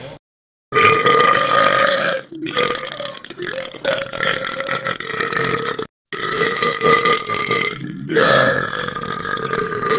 Групповая отрыжка:
burping5.wav